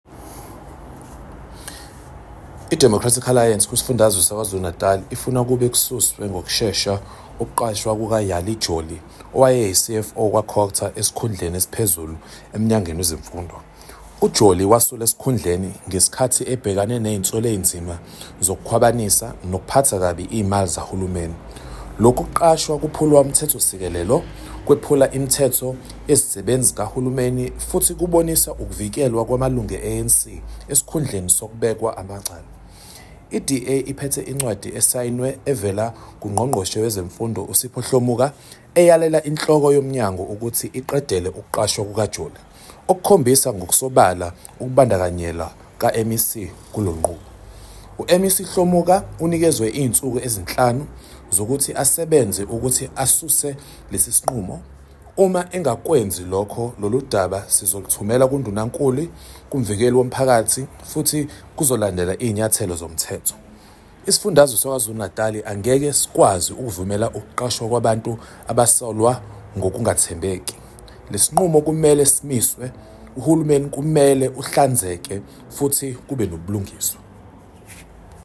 Note to media: find Soundbite by Sakhile Mngadi MPL in English (